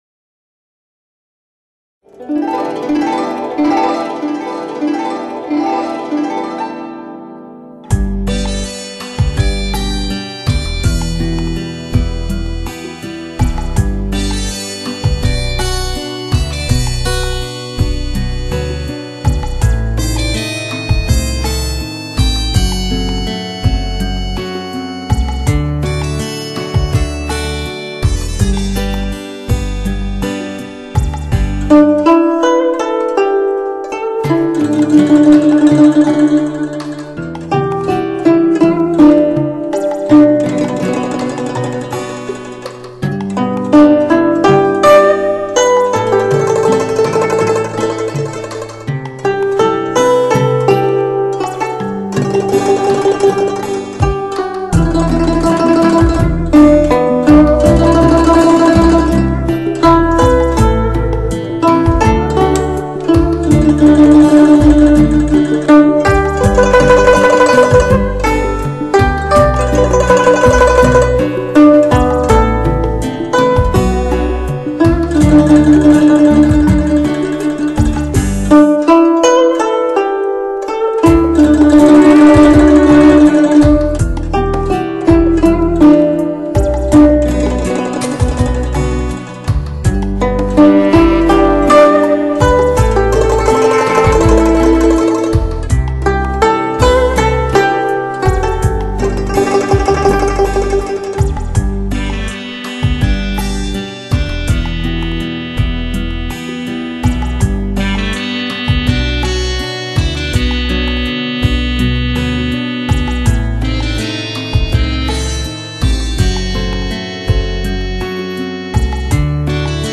经过了几次转换,自然有些损耗,
但音质还是很不错.大家可以试听一下。